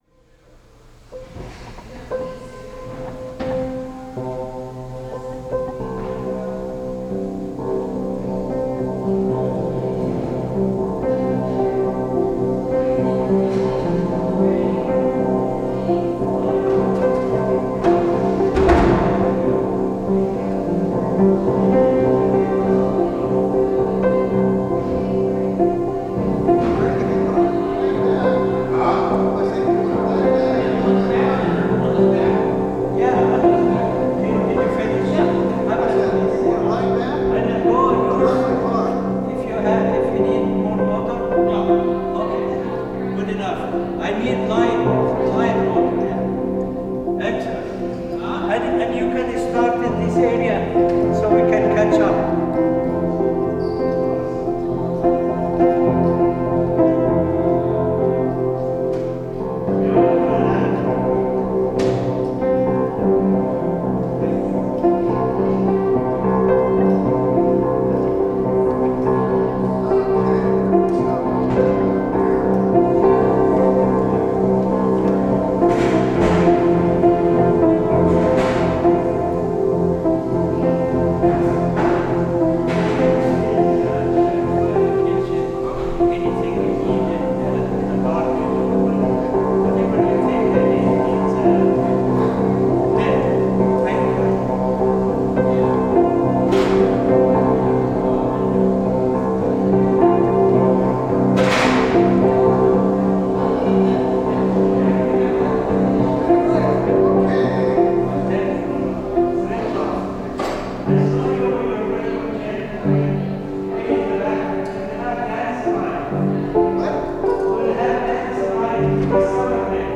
I finally checked it out and couldn’t help but play on the old out of tune piano that was sitting in the church cafeteria.